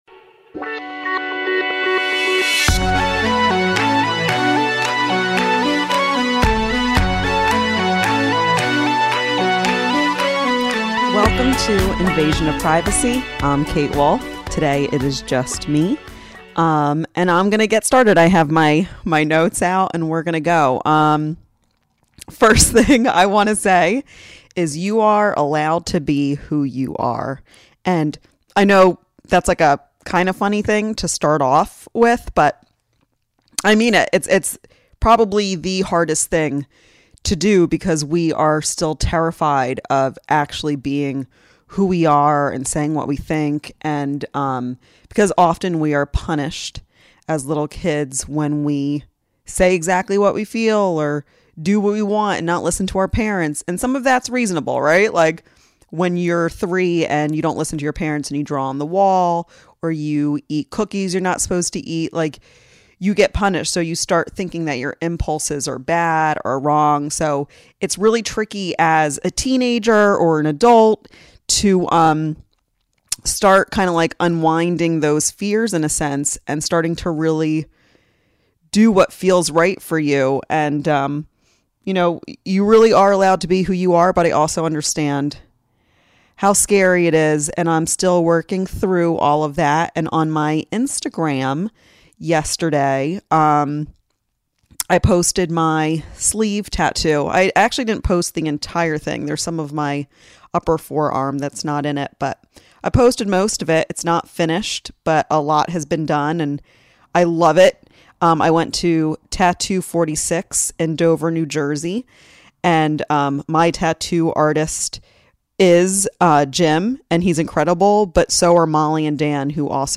She then sings the Shema, reminding everyone we are all one.